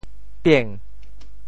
潮州发音 潮州 biêng2